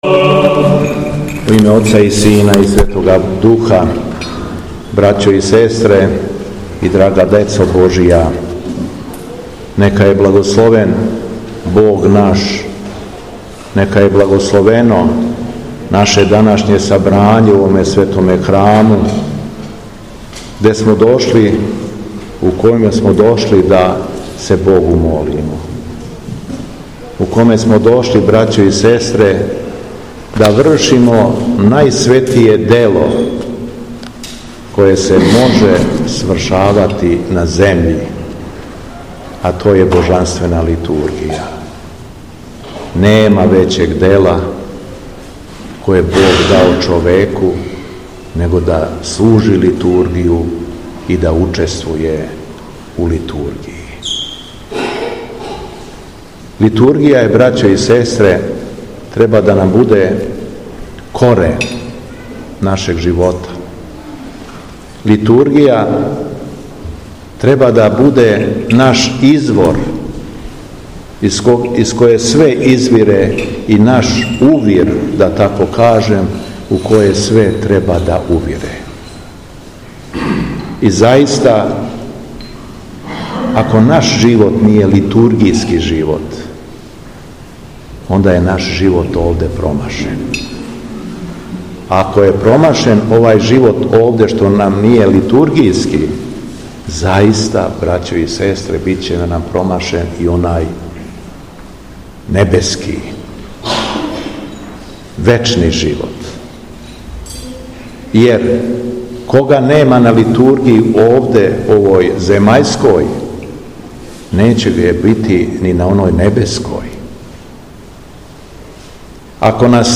Беседа Његовог Високопреосвештенства Митрополита шумадијског г. Јована
Надахнутом беседом Митрополит Јован се обратио окупљеним верницима: